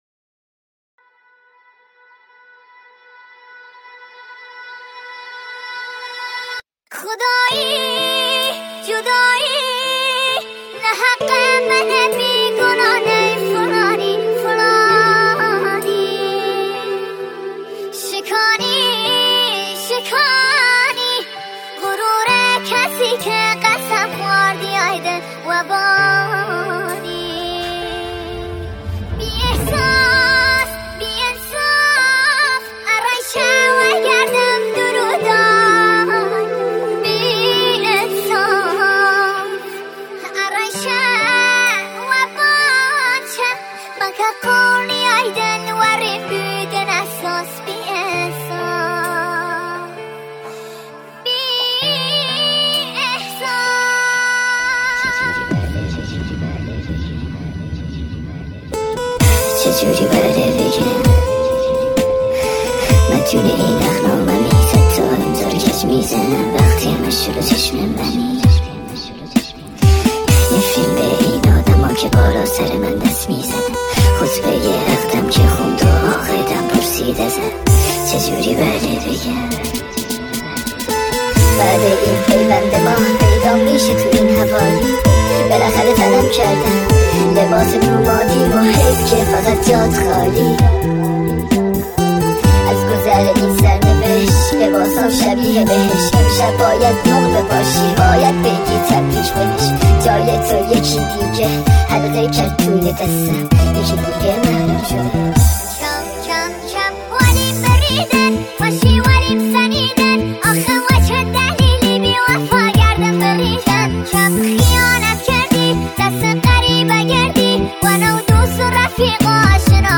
با صدای کلفت صدای مرد